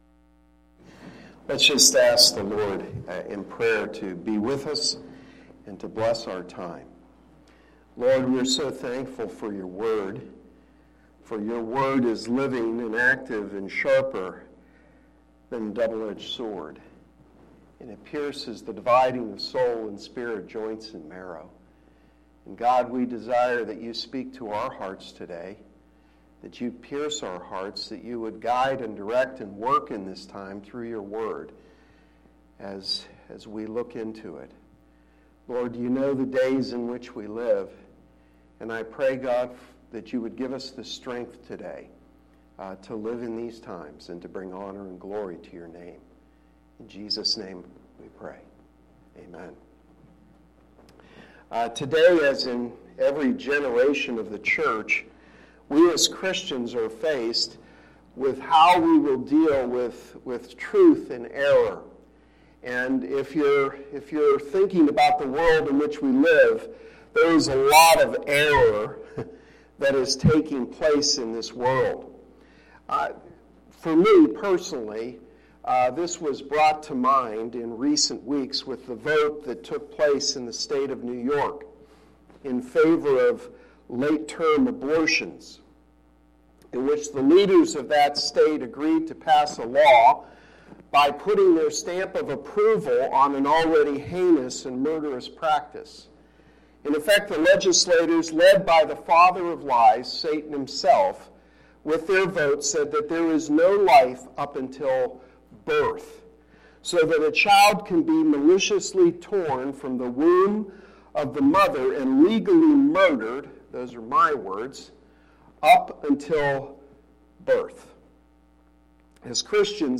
Sermon-3-10-19.mp3